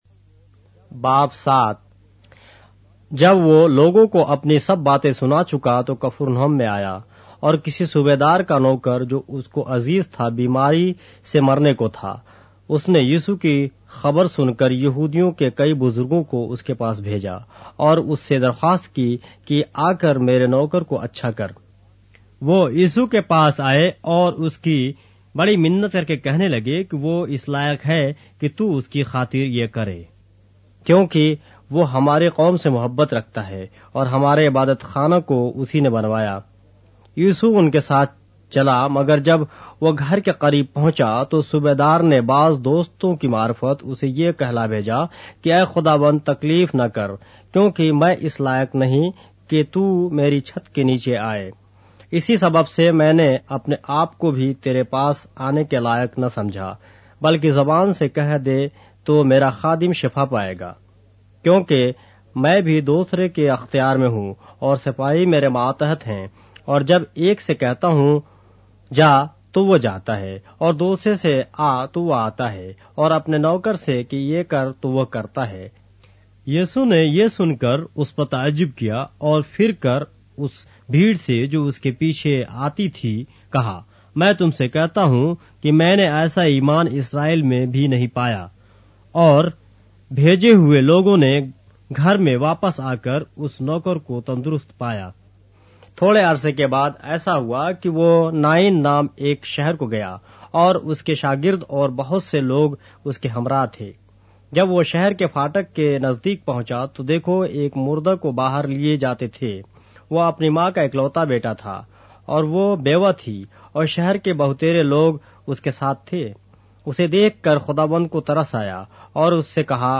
اردو بائبل کے باب - آڈیو روایت کے ساتھ - Luke, chapter 7 of the Holy Bible in Urdu